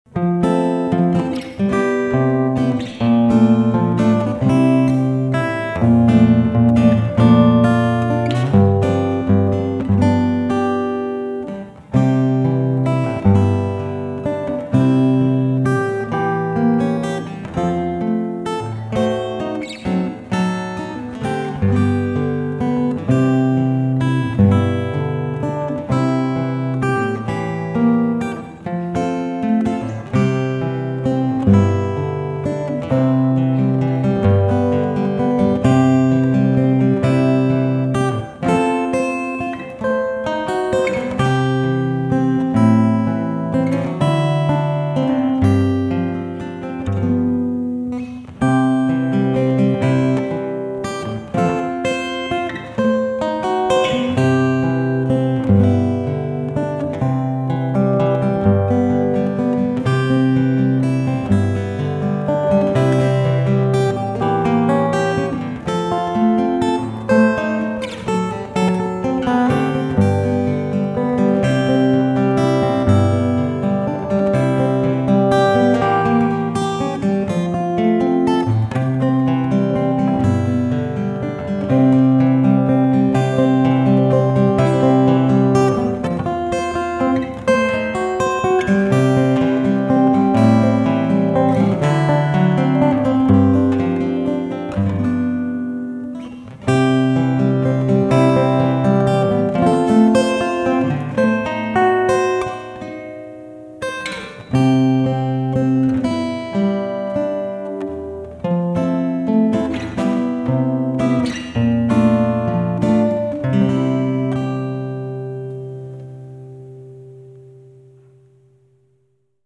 Vocals and music recorded live
Solo fingerstyle guitar.